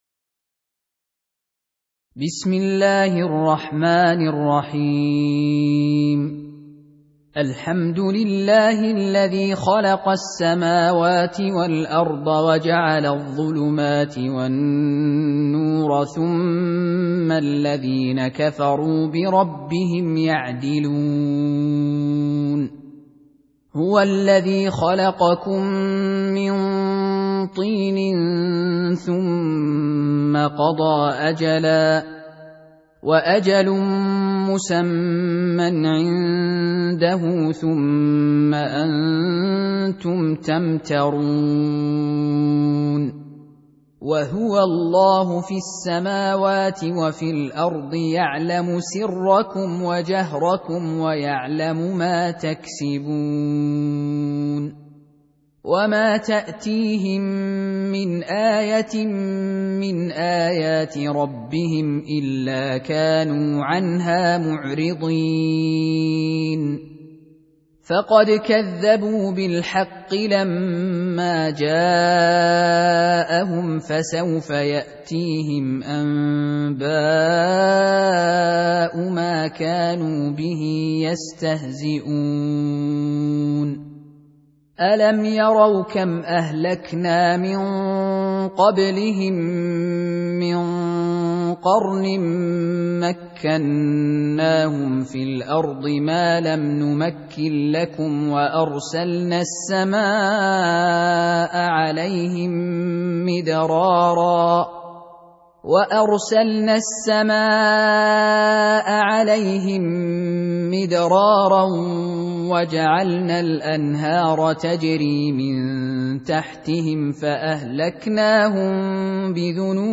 Surah Repeating تكرار السورة Download Surah حمّل السورة Reciting Murattalah Audio for 6. Surah Al-An'�m سورة الأنعام N.B *Surah Includes Al-Basmalah Reciters Sequents تتابع التلاوات Reciters Repeats تكرار التلاوات